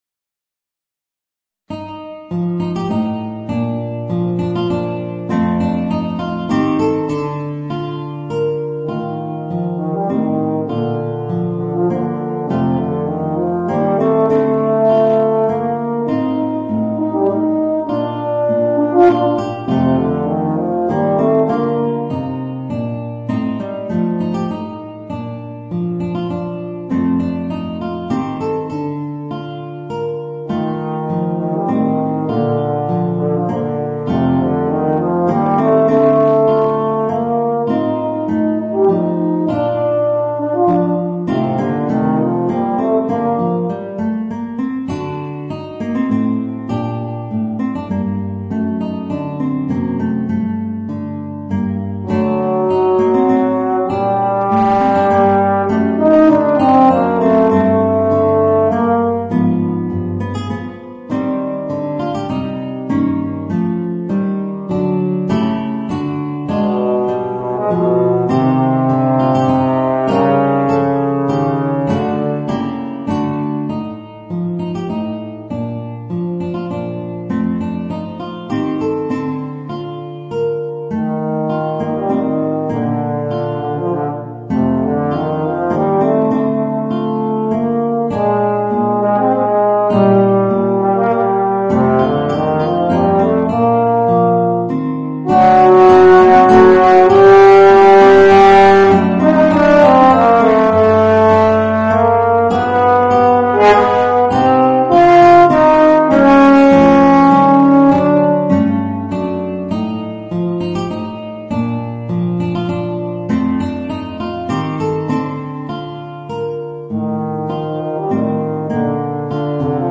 Voicing: Guitar and Euphonium